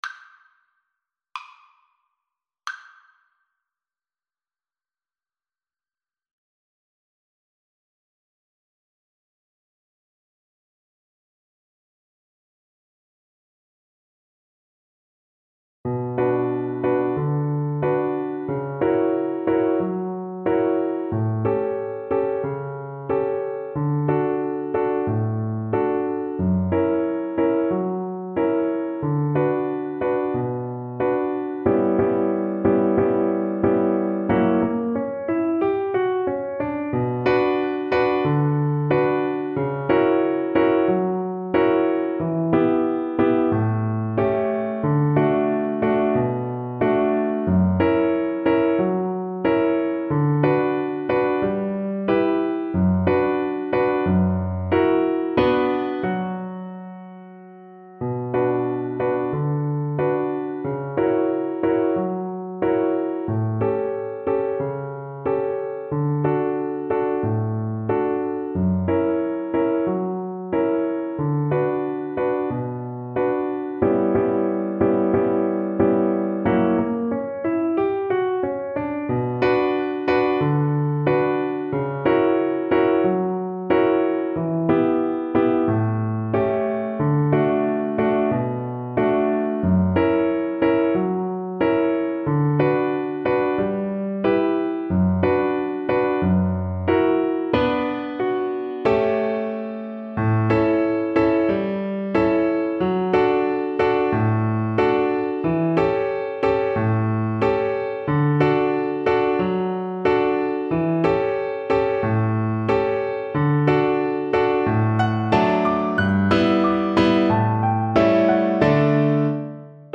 Play (or use space bar on your keyboard) Pause Music Playalong - Piano Accompaniment Playalong Band Accompaniment not yet available transpose reset tempo print settings full screen
Violin
D major (Sounding Pitch) (View more D major Music for Violin )
Slow =c.66 = 66
2/4 (View more 2/4 Music)
Classical (View more Classical Violin Music)